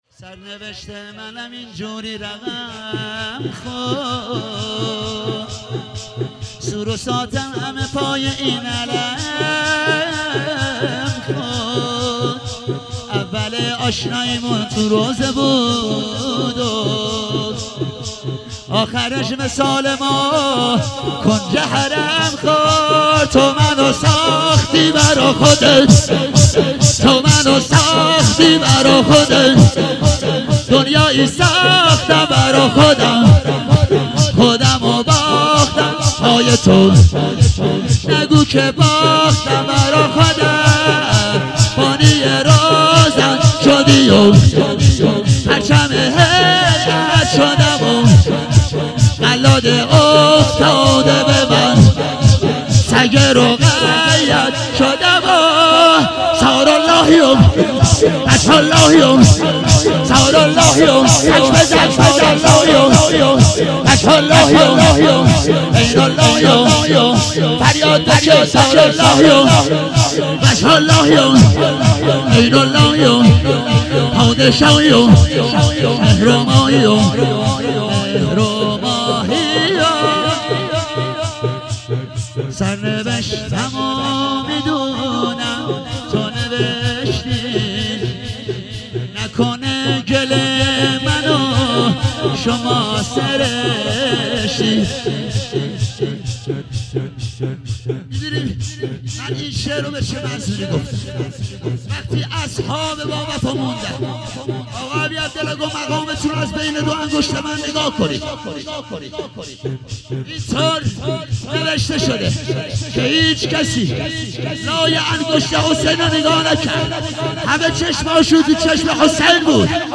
هفتگی زمستان 97